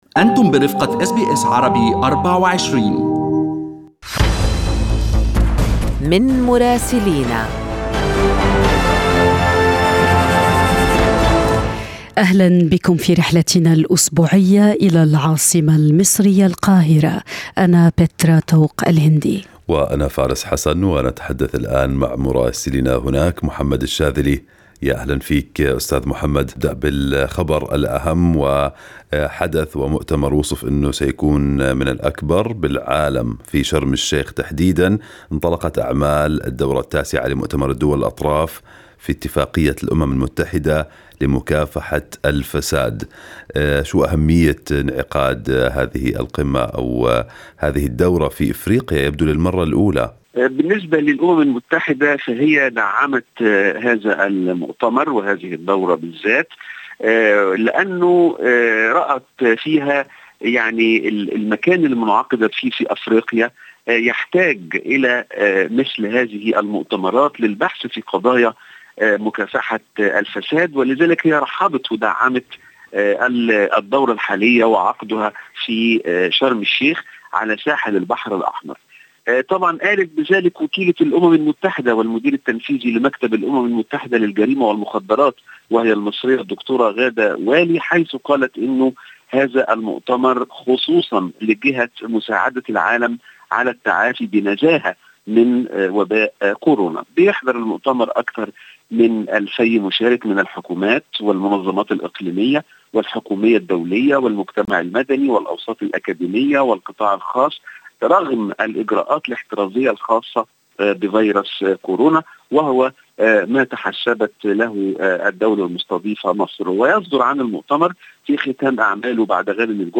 من مراسلينا: أخبار مصر في أسبوع 15/12/2021